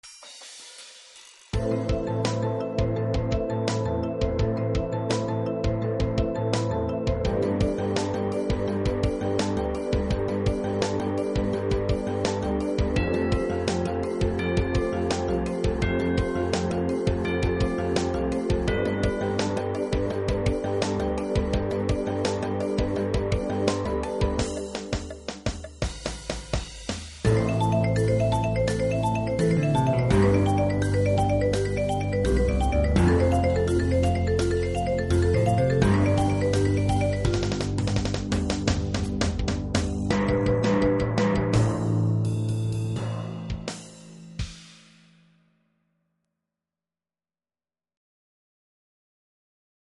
(Percussion Ensemble/Marching Percussion/Indoor Drumline)